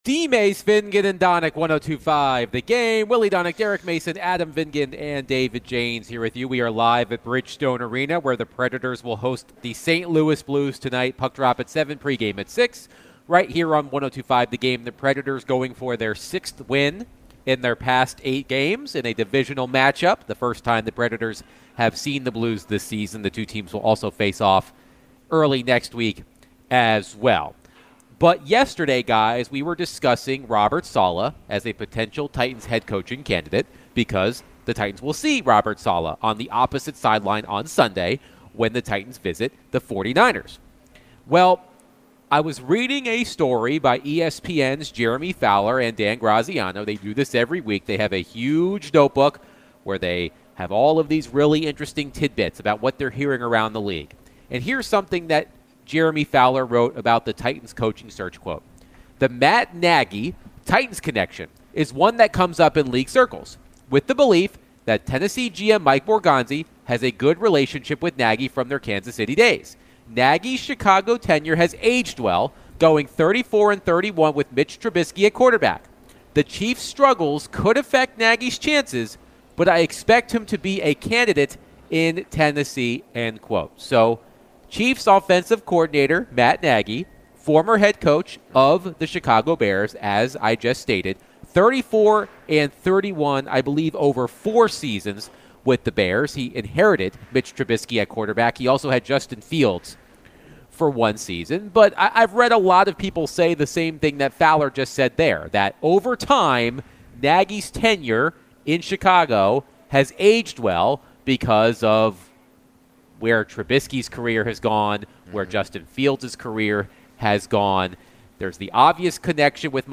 They got a lot of reactions from this, and callers gave their opinions on other coaching candidates.